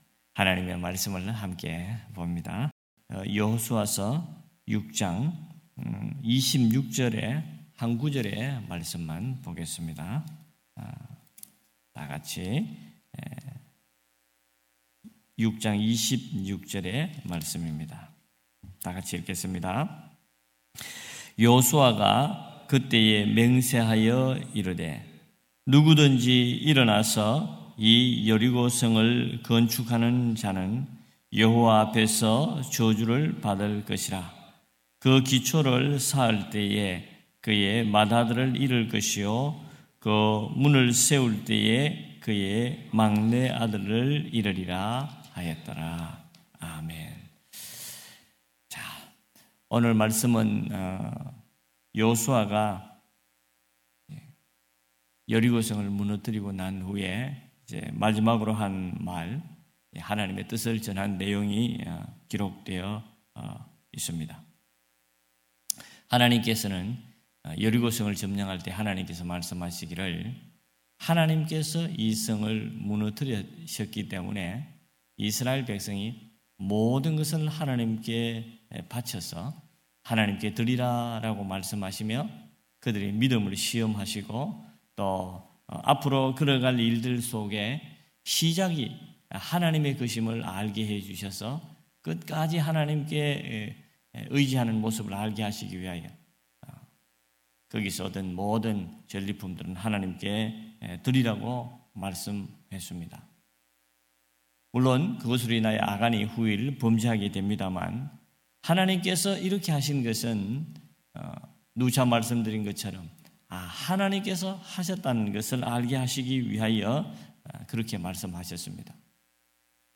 10.18.2025 새벽예배 여호수아 6장 26절